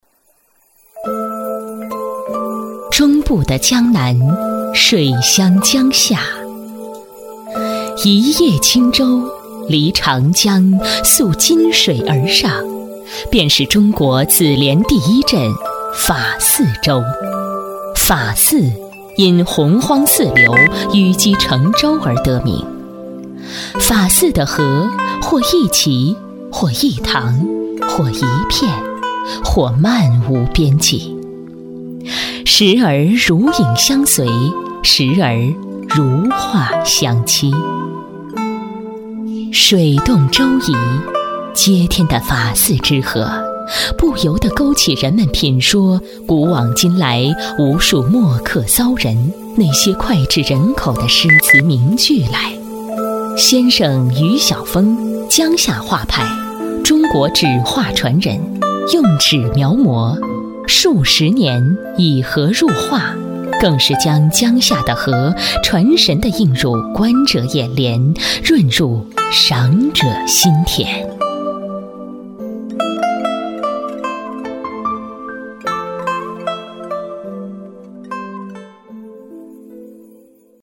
语言：普通话 （44女）
特点：大气浑厚 稳重磁性 激情力度 成熟厚重
风格:大气配音
A44大气韵味--情满水乡 荷润人心.mp3